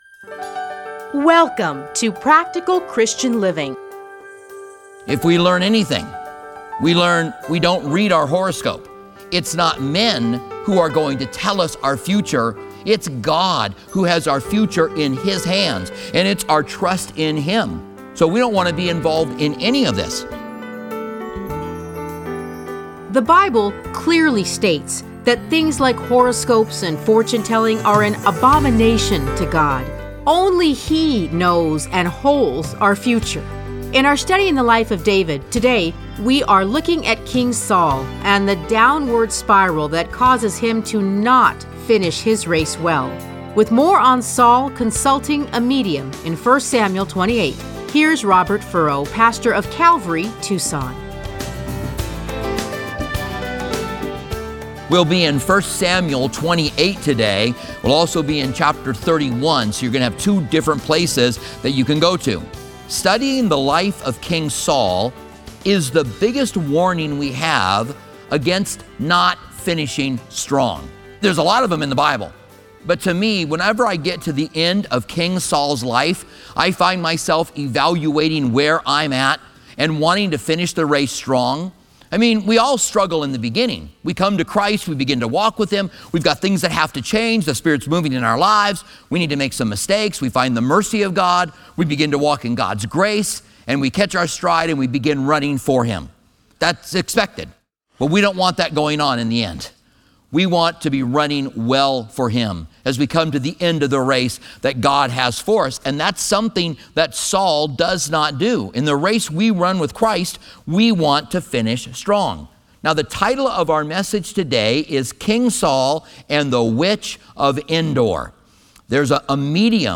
Listen to a teaching from 1 Samuel 31:1-13.